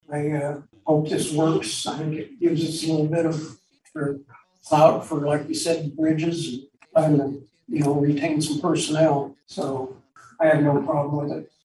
At Monday’s meeting commissioner Pat Weixelman voiced his support for the budget.